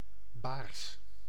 Ääntäminen
IPA : /beɪs/